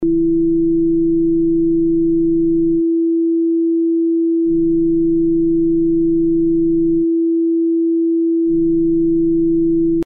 So, also ich habe auch das Multibandcomp. problem mit cubase 32-bit (6er version) + soundfile von der störung, sobald ich bypass des multibandcomp's drücke verschwinden die Obertöne... man kann sie sehr deutlich mit einem kopfhörer warnehmen... system: windows 7 64-bit 4 GB Kingston DDR2, Q6600 Intel, Asus P5E delxue Mainboard, 500GB HDD, RME Fireface 400 und ein paar gute kopfhörer!